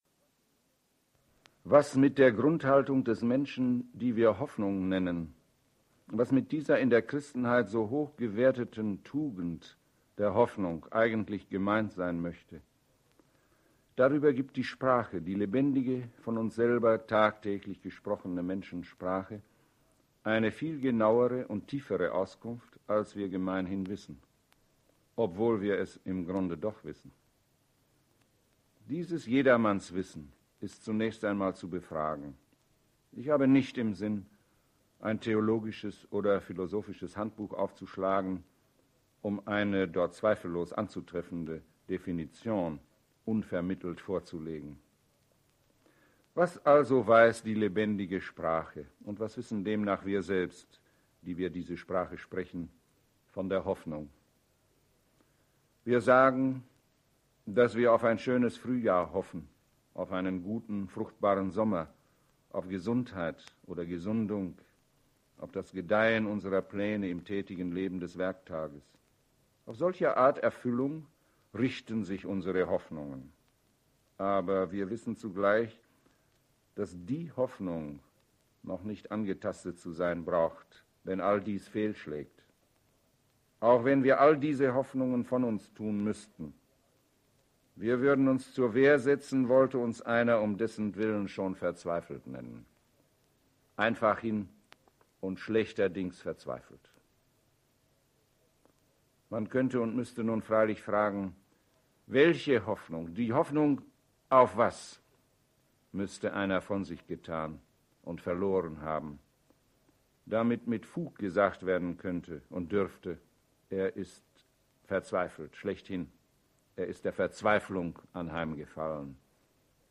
MP3: Prof. Josef Pieper, Vortrag
MP3_Prof-Josef-Pieper_Hoffnung-und-Hoffnungslosigkeit_Vortrag_96kbt.mp3